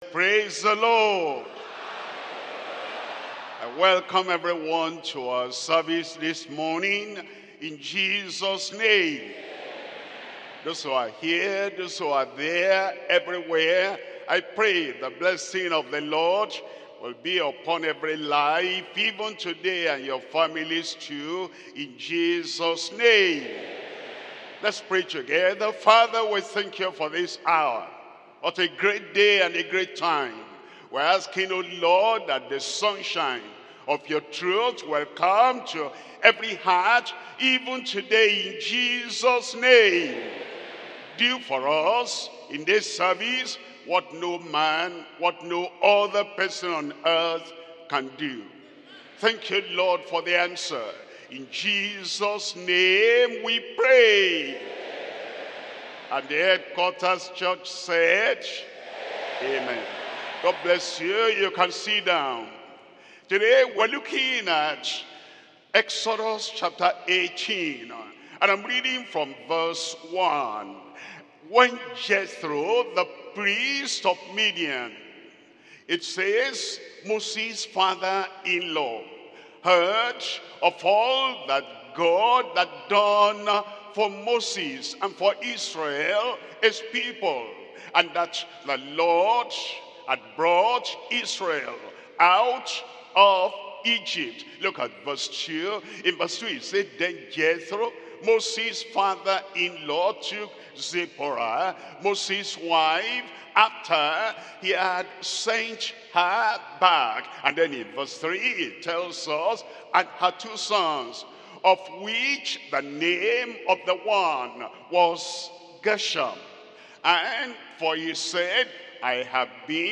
Sermons - Deeper Christian Life Ministry
2025 Global Family and Marriage Conference